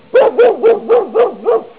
cane.wav